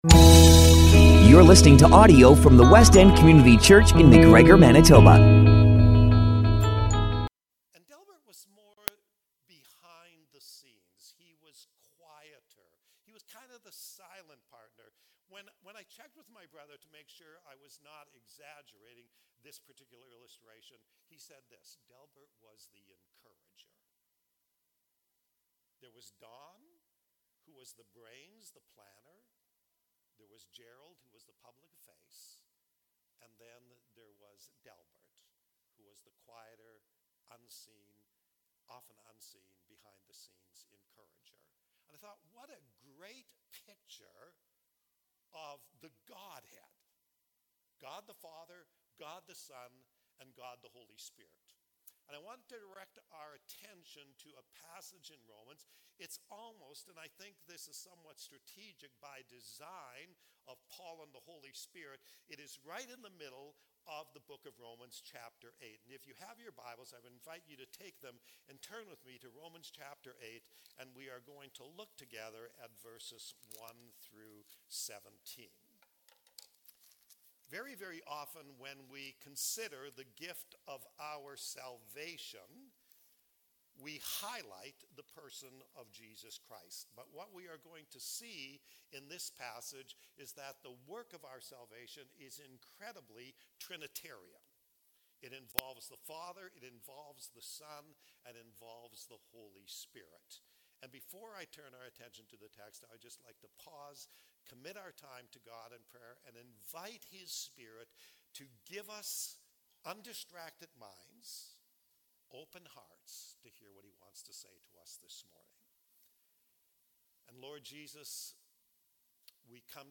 Sermons from the Westend Community Church